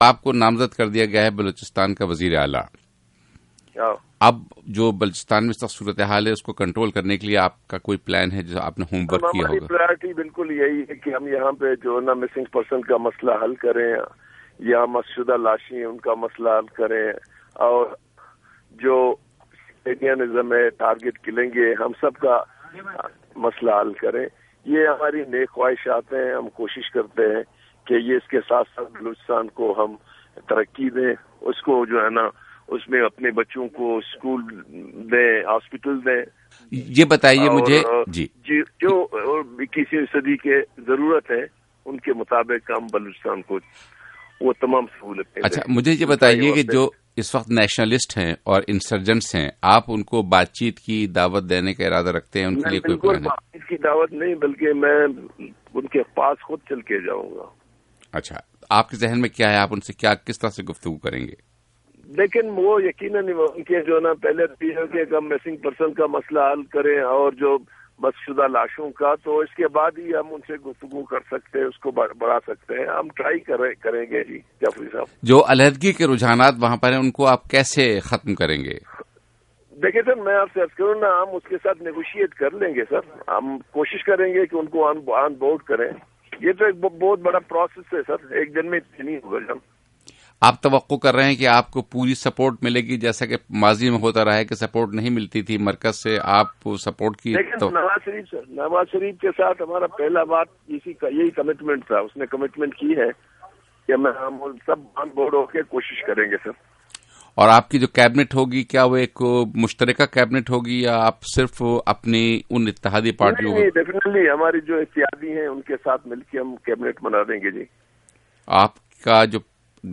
نامزد وزیر اعلیٰ، ڈاکٹر عبدالمالک کا انٹرویو